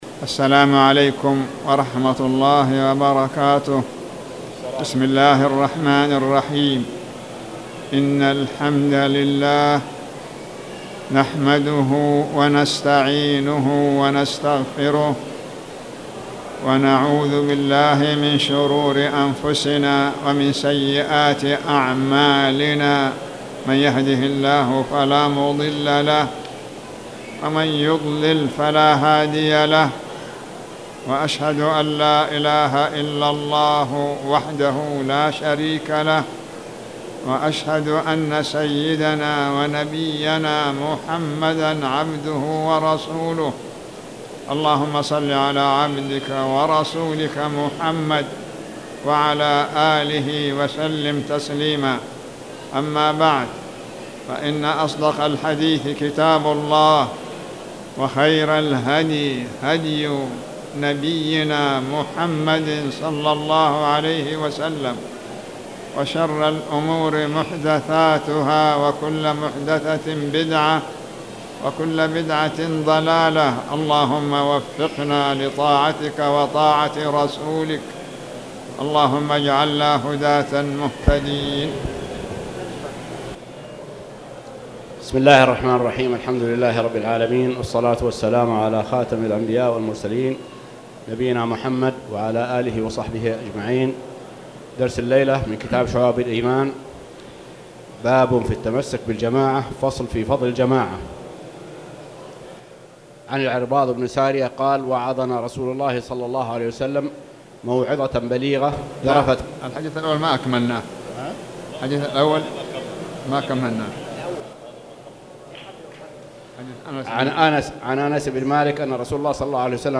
تاريخ النشر ٢١ ربيع الأول ١٤٣٨ هـ المكان: المسجد الحرام الشيخ